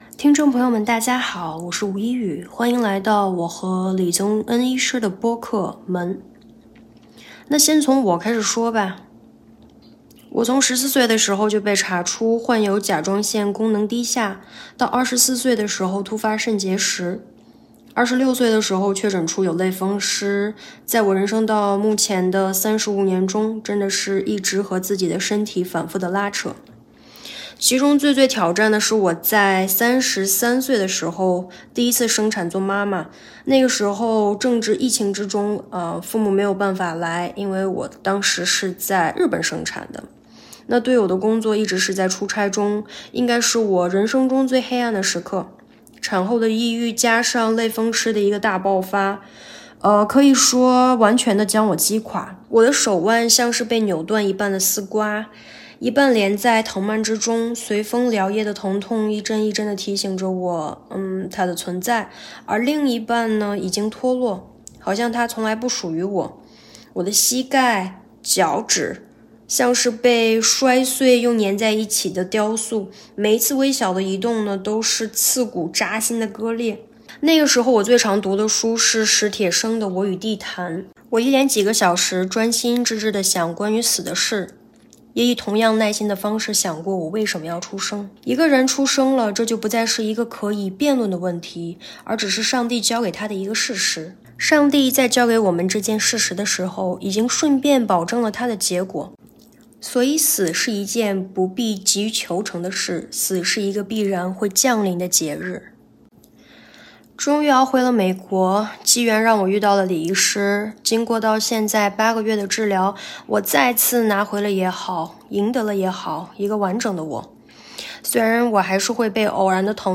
目前沒有特定的題材，也沒有特定的時間表，隨性也隨時間，藉由主持人的提問，和大家聊一聊。